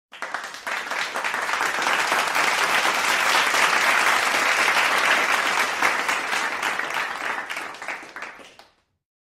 applause sound sound effects
applause-sound